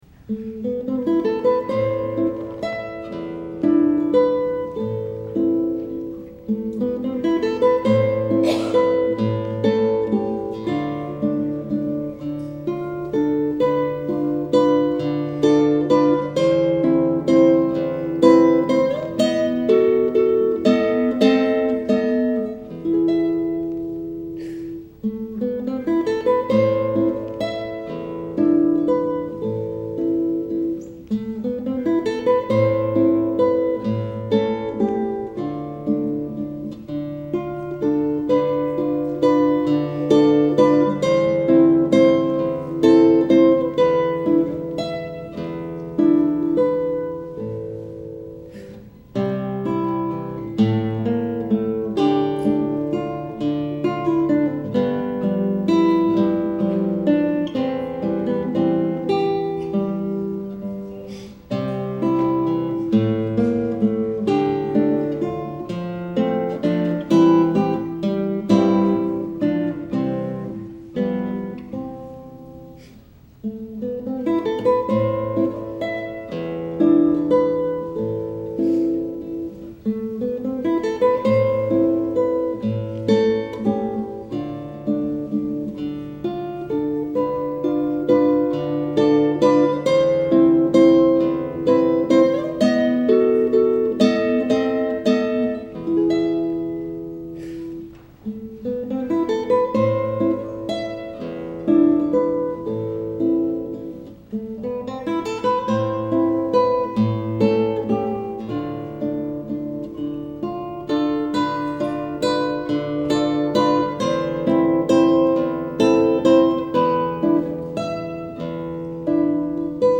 マリア・ルイサ2   ゆっくり演奏70